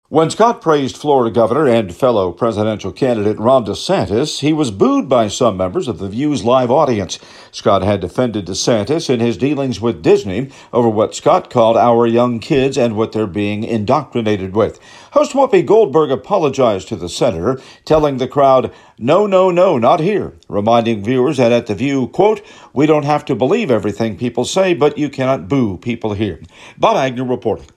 Reporting from Capitol Hill
When Scott praised Florida governor —and fellow presidential candidate- Ron DeSantis, Scott was booed by some members of The View’s live audience.